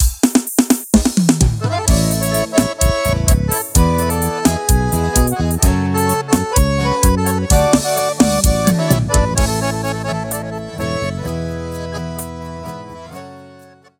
• Demonstrativo Arrochanejo:
• São todos gravados em Estúdio Profissional, Qualidade 100%